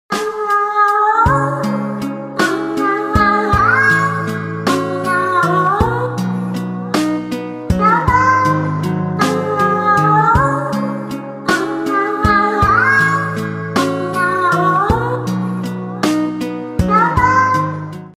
Кошка поёт